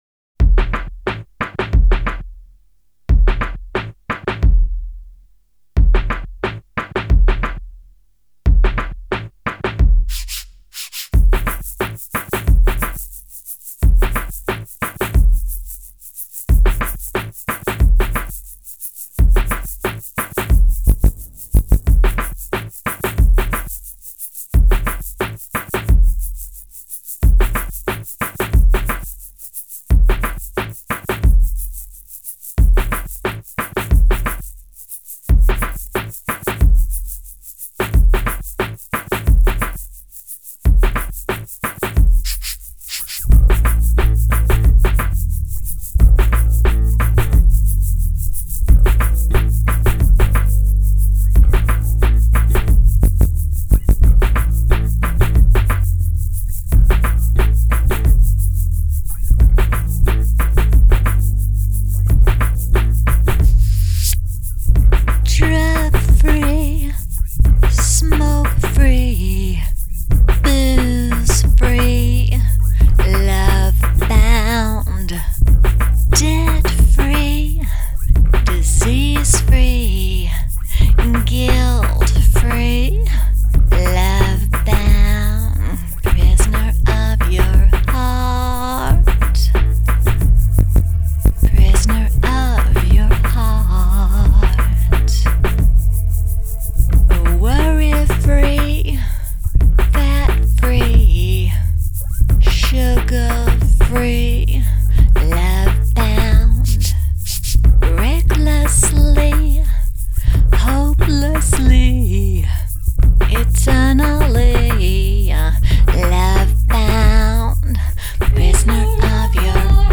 in a stylistic groove that merges to 70’s rock